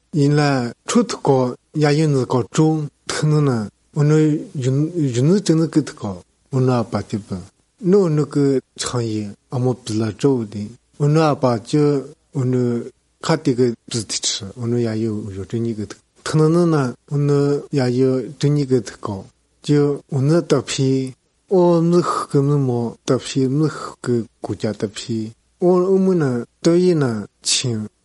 3 June 2019 at 1:29 am Nasals, ejectives, and an apparent absence of /e/.